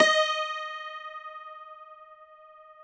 53q-pno15-D3.aif